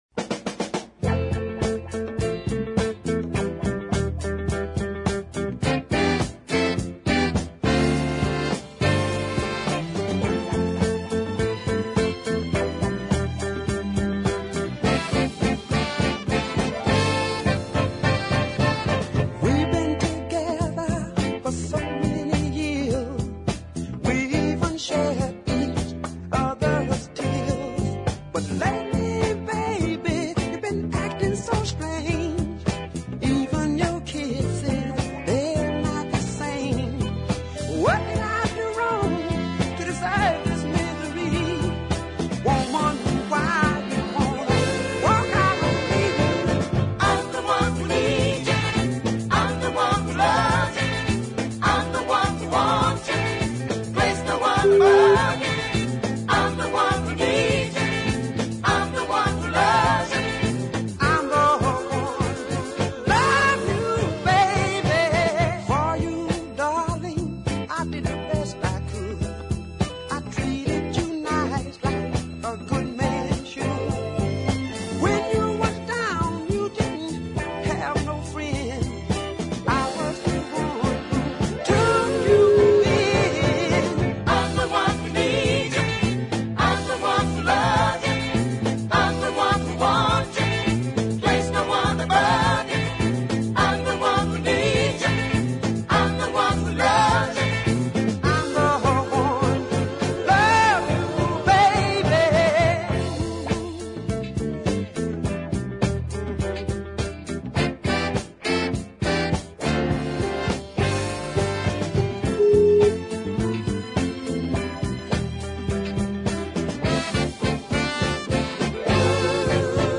A southern soul cult figure
midpaced dancer